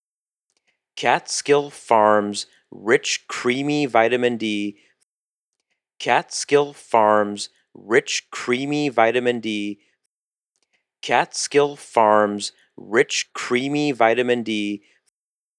How can I make my VoiceOver sound better
I have done the usual stuff you learn on YouTube, like filter curve EQ, compression, normalization.
Sounds like compression has exaggerated the sibilance.
There is a little reverb from the room.